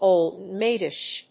音節óld‐máid・ish 発音記号・読み方
/‐dɪʃ(米国英語)/